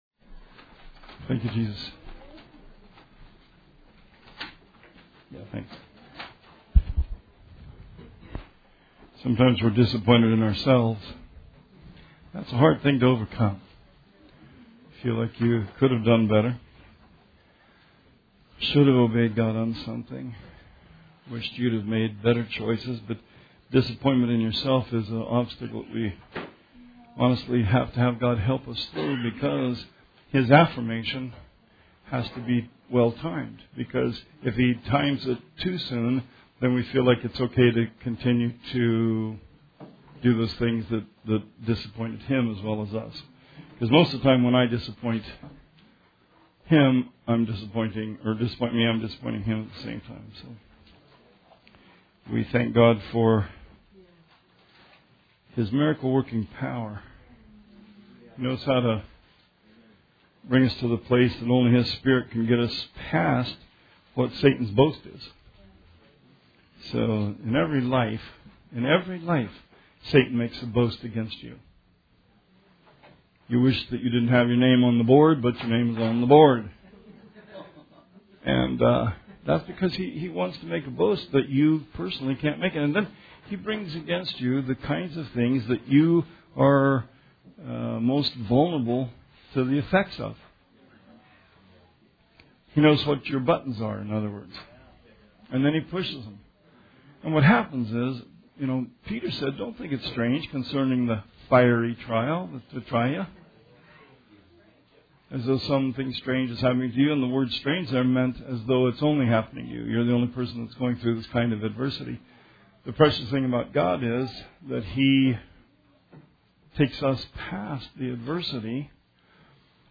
Sermon 4/29/18